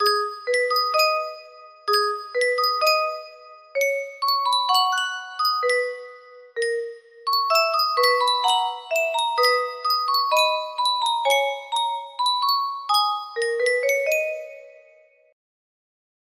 Yunsheng Music Box - Shuuchisin 2315 music box melody
Full range 60